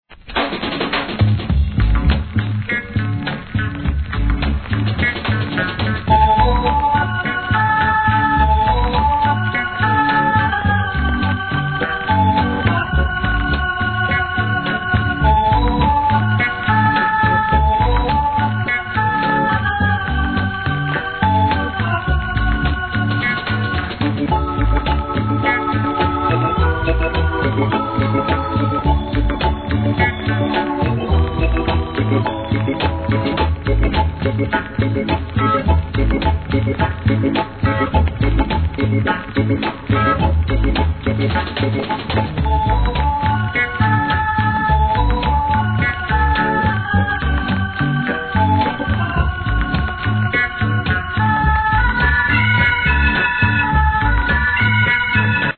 7inch
REGGAE